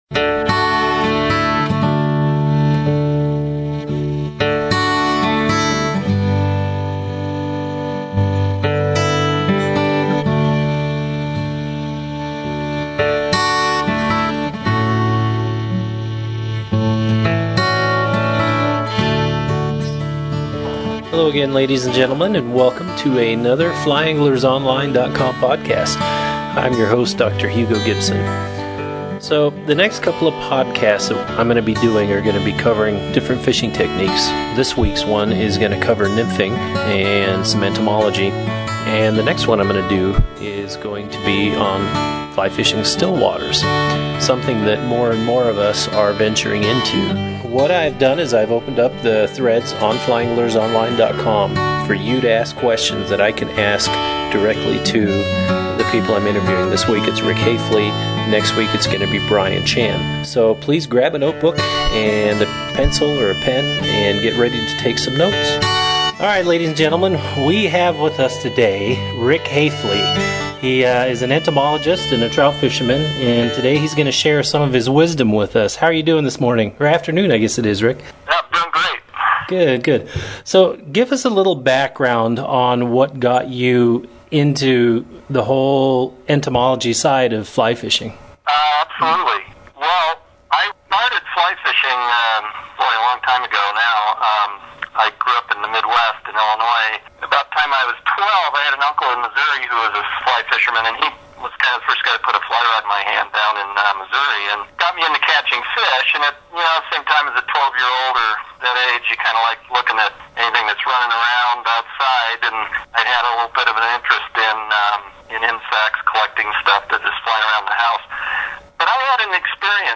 This is a great interview and is very informative.